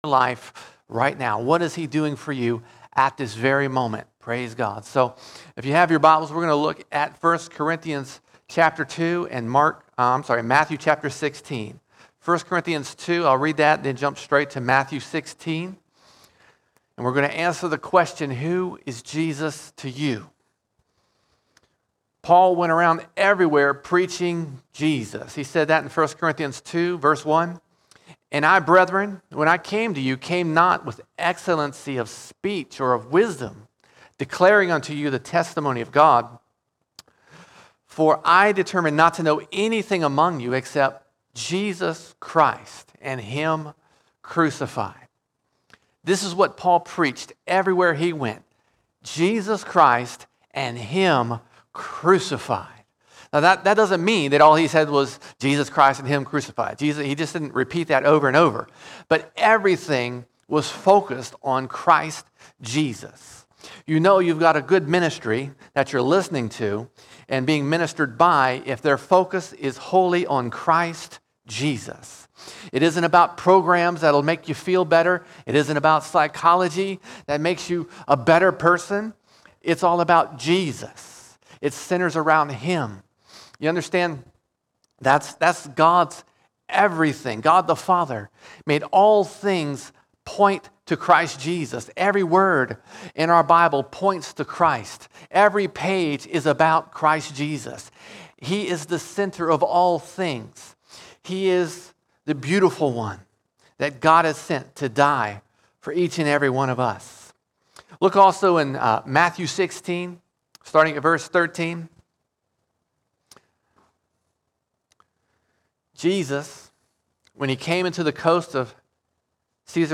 2 October 2023 Series: Sunday Sermons Topic: Jesus All Sermons Who Is Jesus To You?